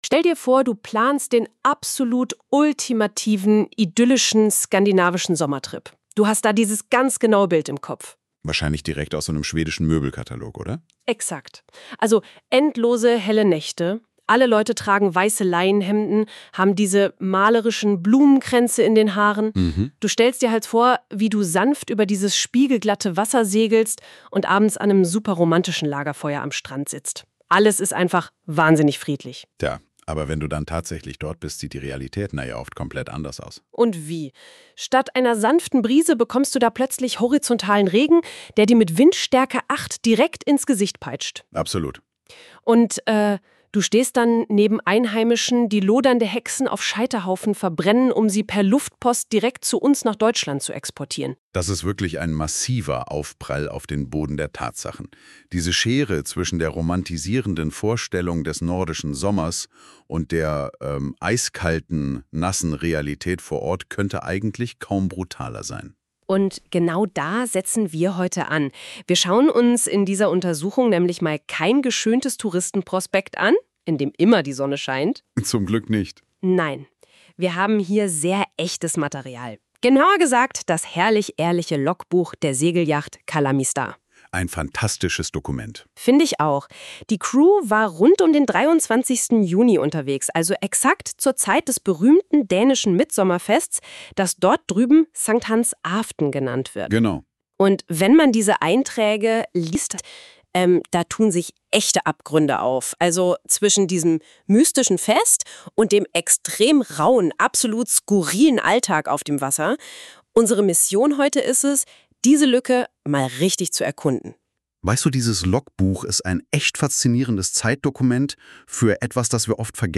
Mit kleiner aber feiner Besatzung segeln wir in den Mittsommer. Trotz Sonne, Sturm & Regen ein wunderbarer Törn - auch gegen den Strom. Das Logbuch und der von NotebooksLM daraus erzeugte Podcast